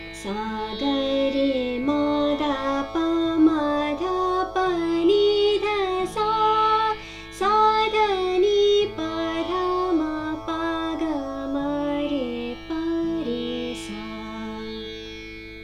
The solfege syllables Sa-Ri-Ga-Ma-Pa-Da-Ni-Sa are used for all scales.
Raag Gaud-Sarang (oxatonic)
Gaud-Sarang is a sunshiny, lively early-afternoon raga.